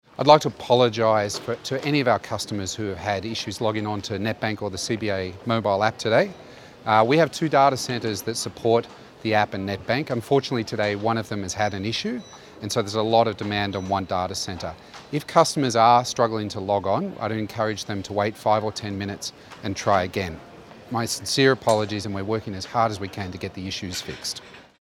Radio grabs